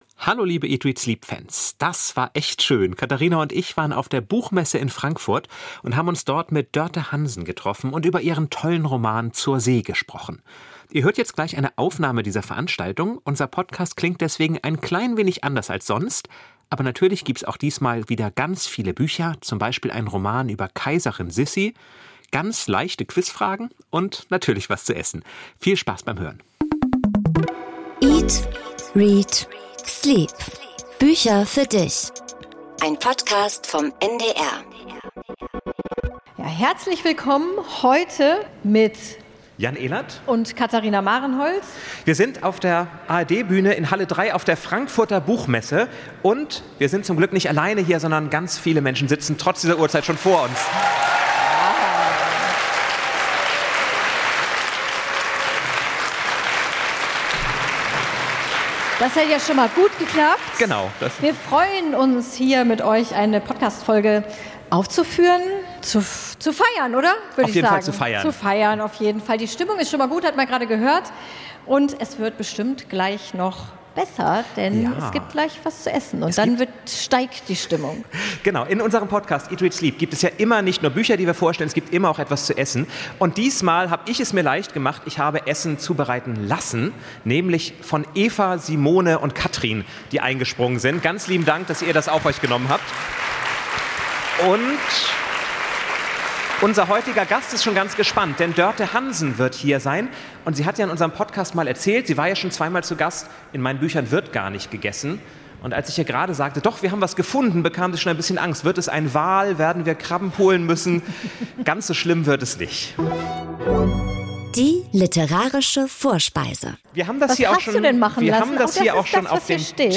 Das alles live auf der Frankfurter Buchmesse. Das Quiz, mit Publikumsbeteiligung, strotzt vor herrlich unnützem Partywissen.